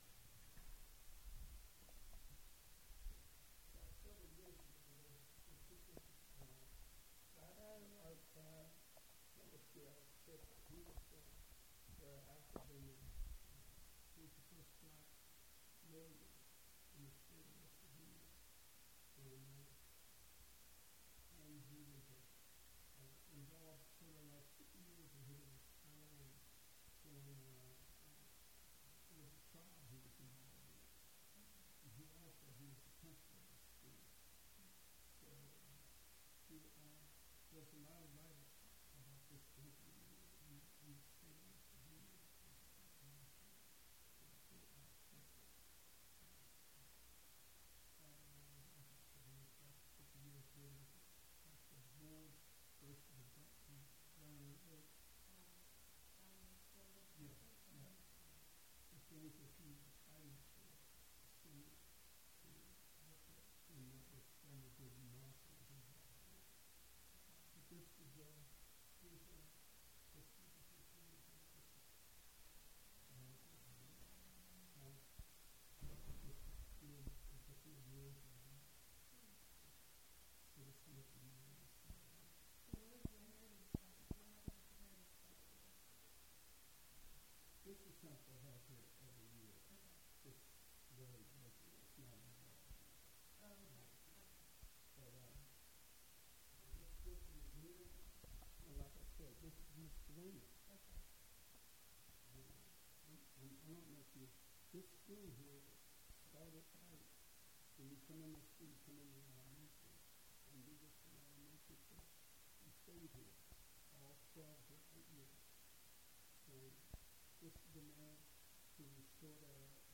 , and School integration Rights: In Copyright - Educational Use Permitted Location: Martinsburg (W. Va.)